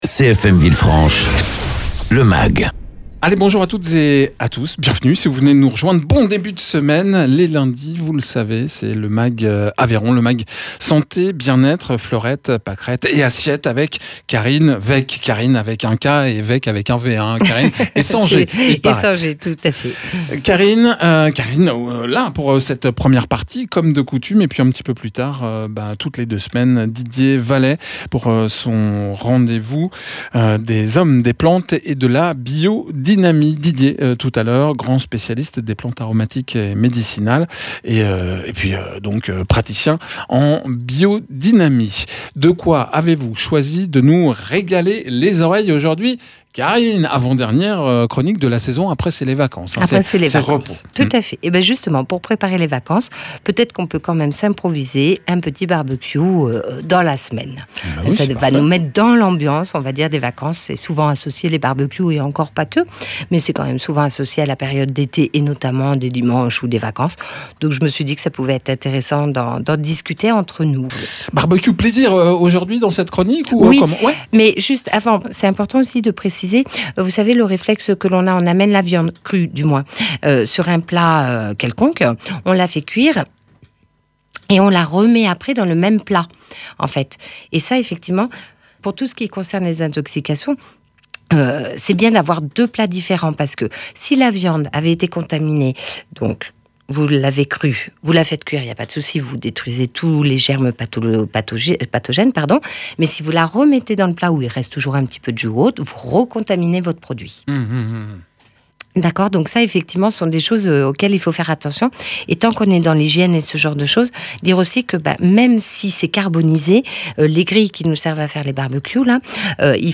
nutritionniste diététicien
paysan spécialisé dans les plantes aromatiques et médicinales et en biodynamie